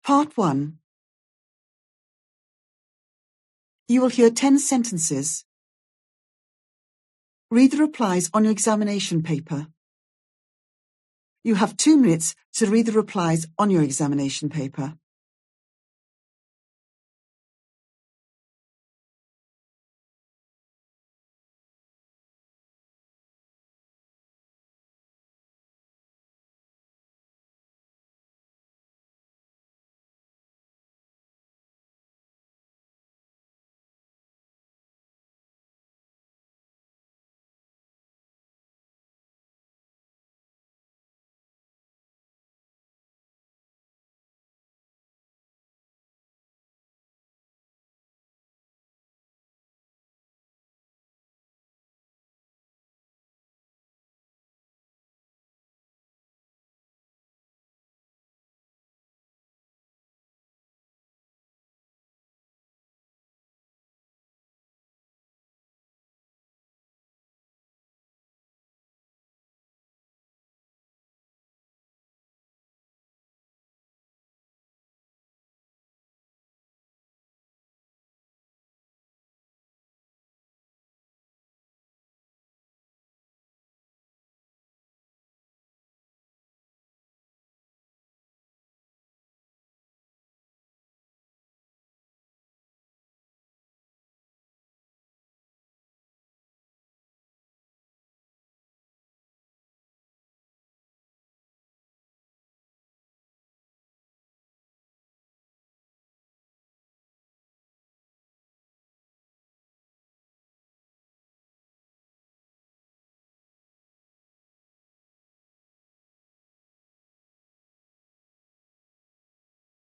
You will hear ten sentences twice.